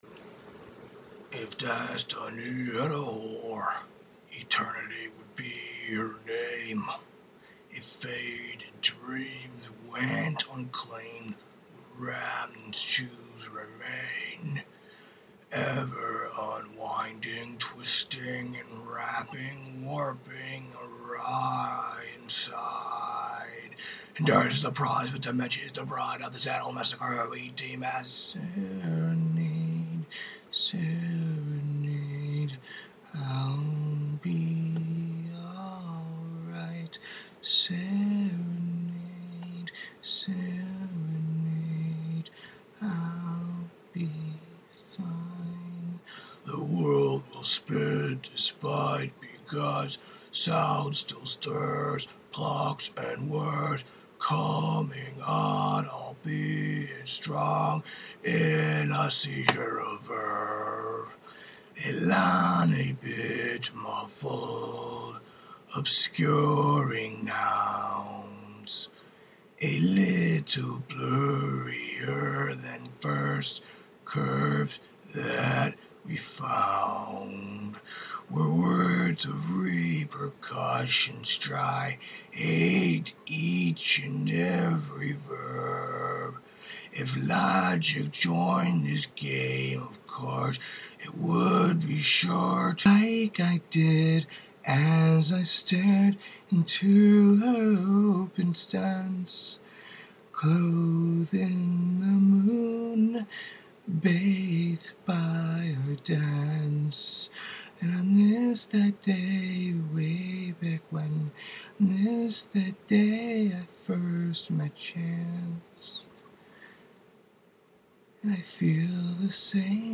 Poetry, Lyrics, Song, Singing